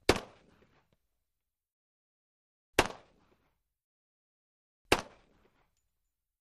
.45 Caliber Pistol: Single Shots. Stereo